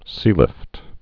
(sēlĭft)